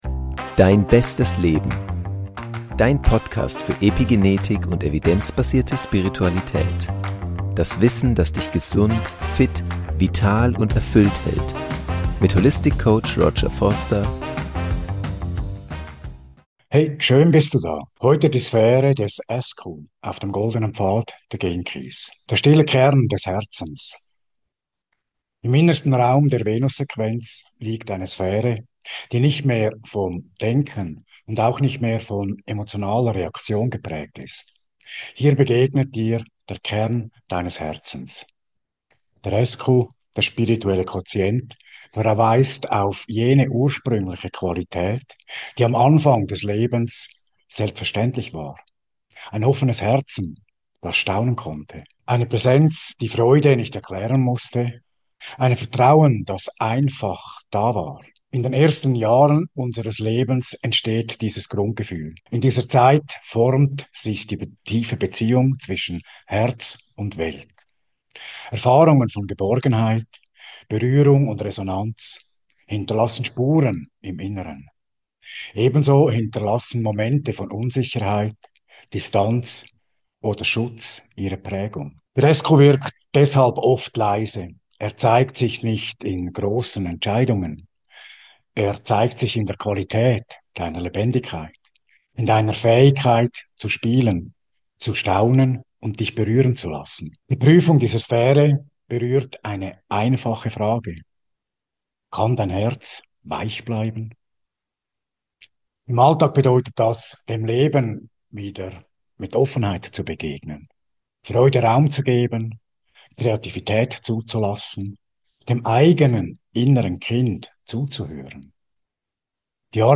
Meditation zur Sphäre des SQ
Sanft, ruhig und mit genügend Raum für innere